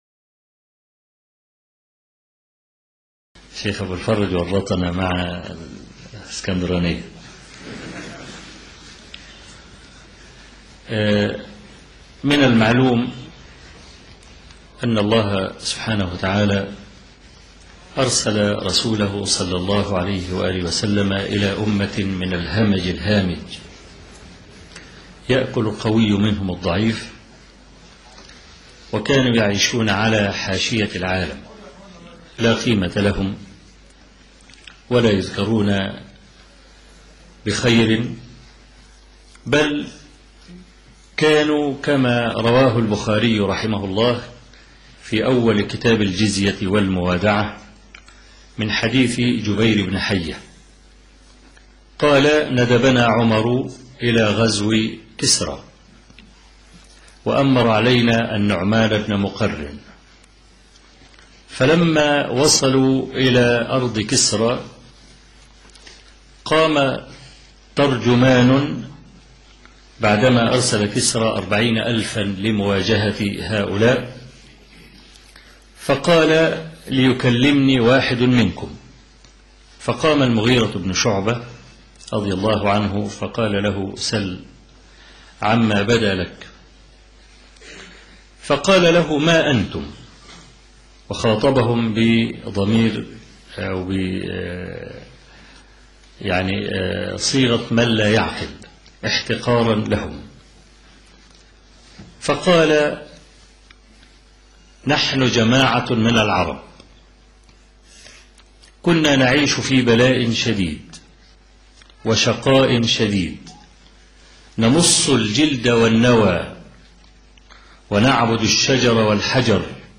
لقاء الإسلام سبيل العزة العربية (كلمة ألقاها فضيلة الشيخ أبو إسحاق الحويني - رحمه الله - الشيخ أبو إسحاق الحويني